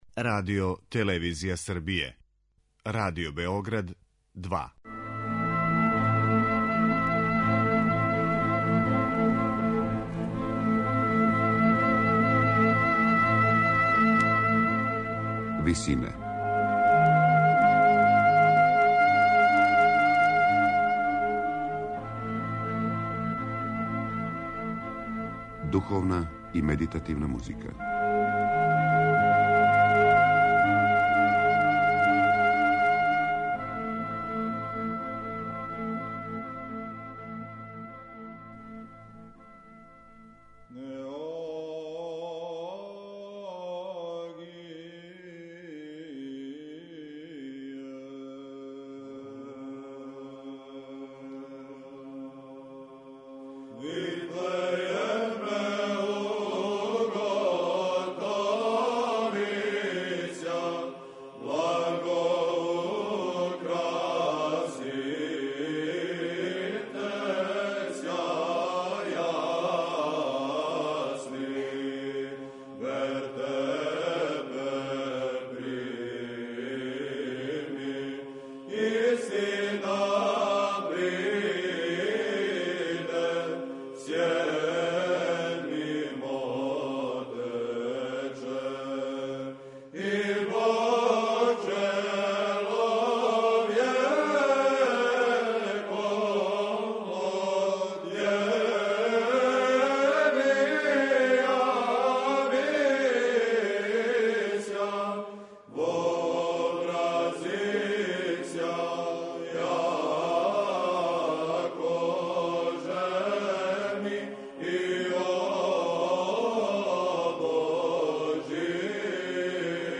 раскошном и разноврсном хорском слогу
Снимак који ћемо емитовати остварио је Хор "Православна отаџбина".